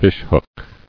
[fish·hook]